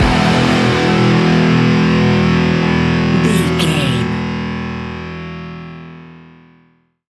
Ionian/Major
hard rock
heavy rock
distortion
instrumentals